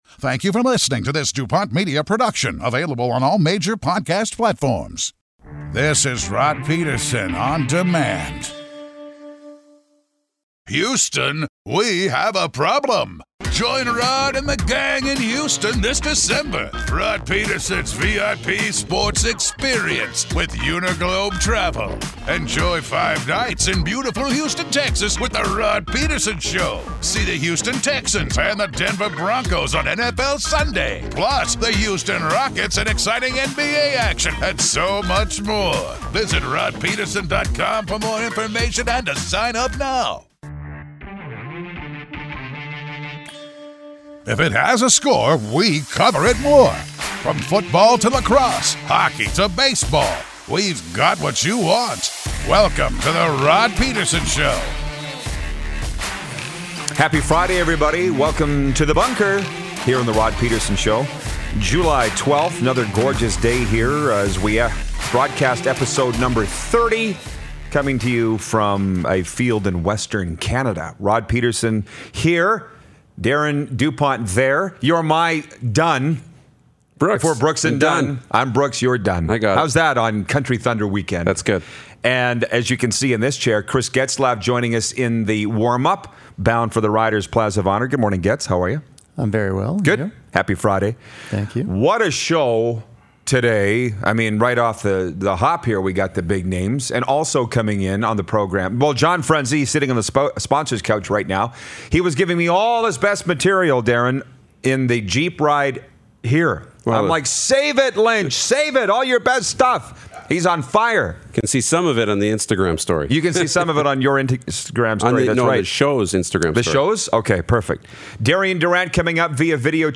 A BONKERS show with Chris Getzlaf with Darian Durant on video chat!